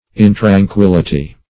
Search Result for " intranquillity" : The Collaborative International Dictionary of English v.0.48: Intranquillity \In`tran*quil"li*ty\, n. Unquietness; restlessness.